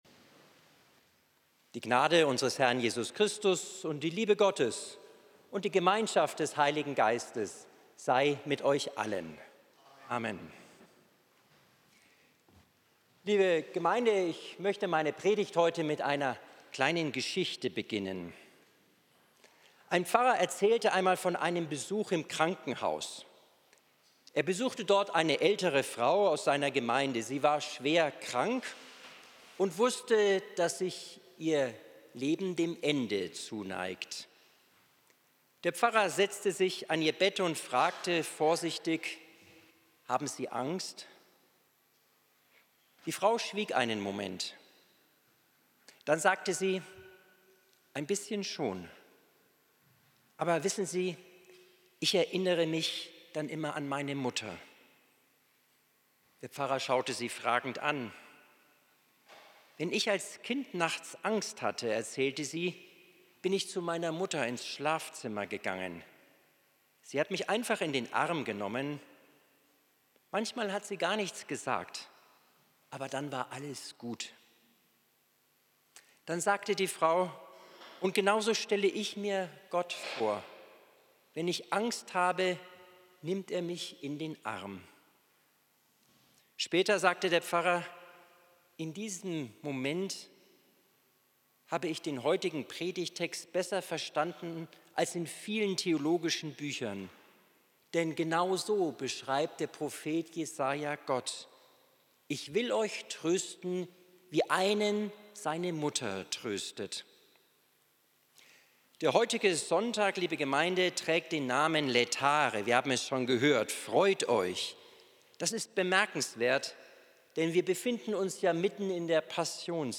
Predigt vom 08.03.2026 Spätgottesdienst